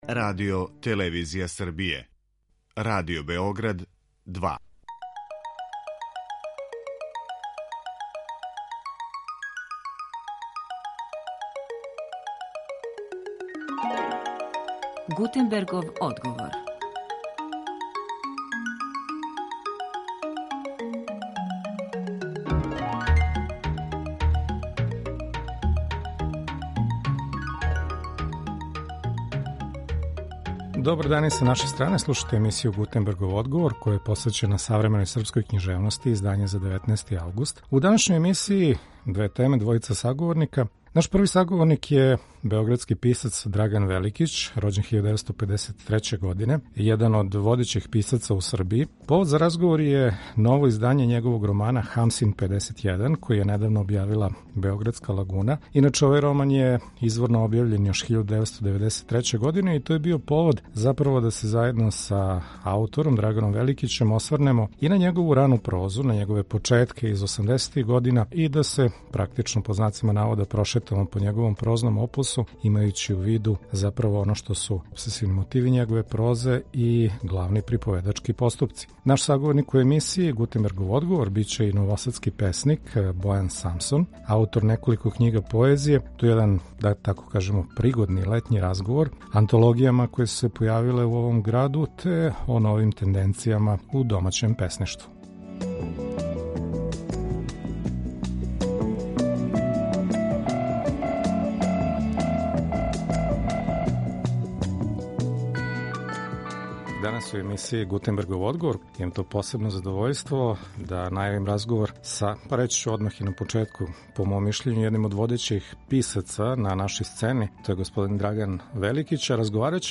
Први наш саговорник је Драган Великић (Београд, 1953), један од водећих писаца у Србији.